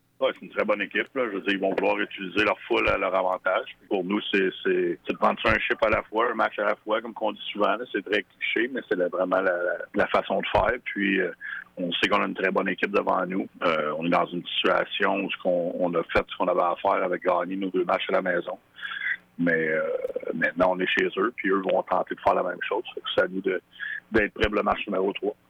Lorsque les représentants des médias l’ont contacté par téléphone